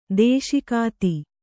♪ dēśikāti